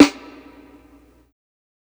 Snares
ILLMD010_SNARE_FIRSTTIME.wav